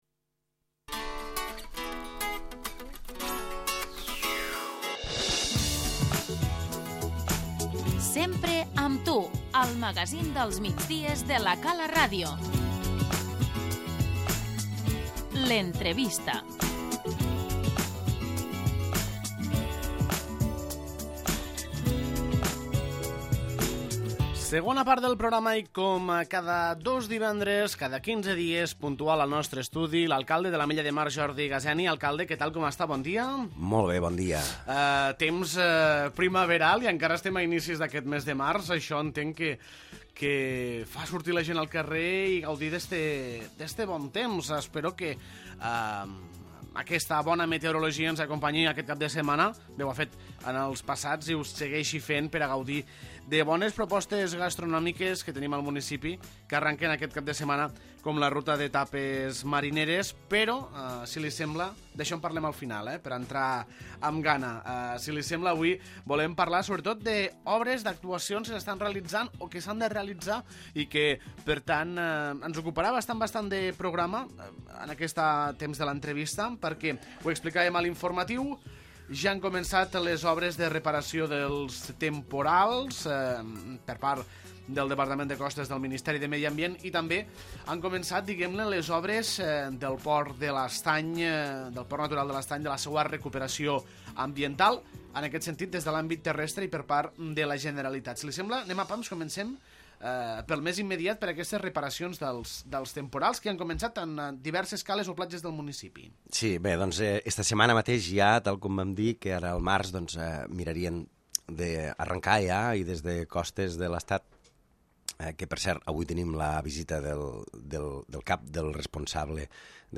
L'entrevista - Jordi Gaseni, alcalde de l'Ametlla de Mar